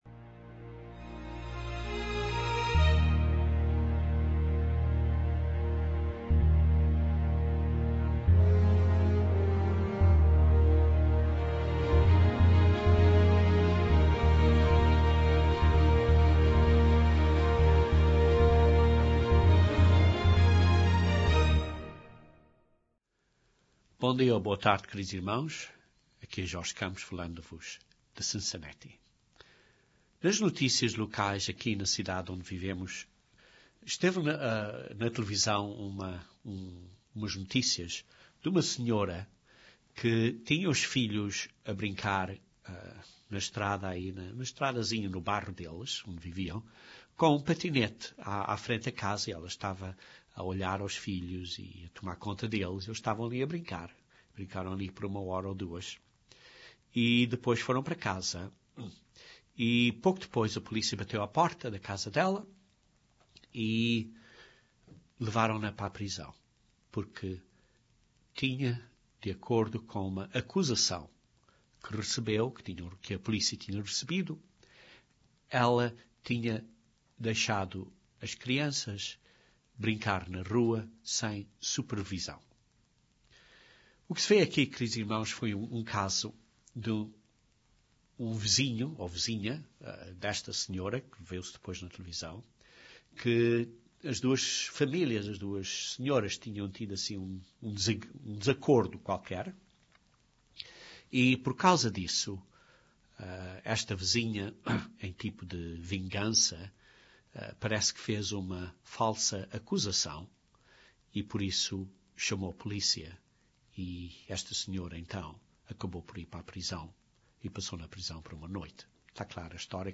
O diabo odeia o plano de Deus para a humanidade e por isso odeia a nós, principalmente aqueles que estão a tentar agradar a Deus. Este sermão descreve 4 ciladas do diabo, entre muitas outras que ele tem.